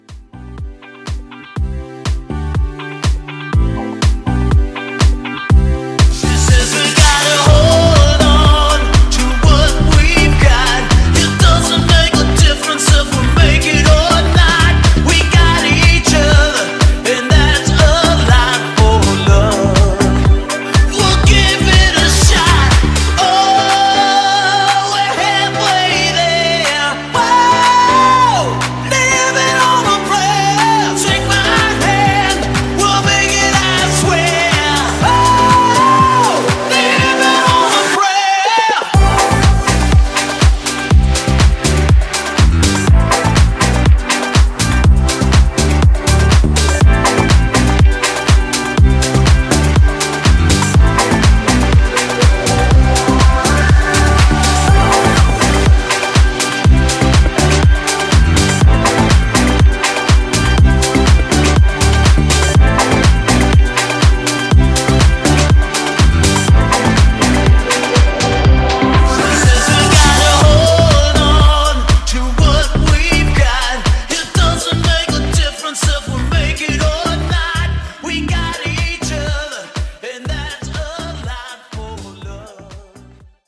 BOOTLEG , DANCE , EDM Version: Clean BPM: 126 Ti